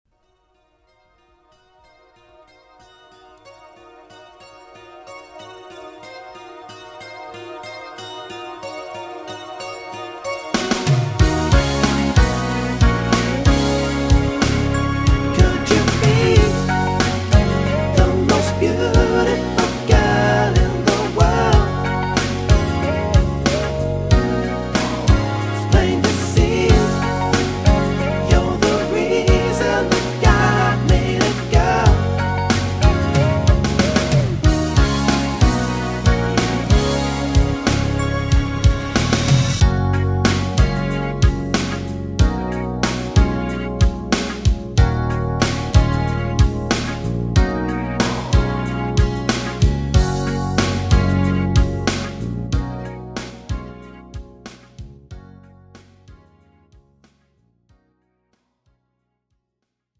장르 pop 구분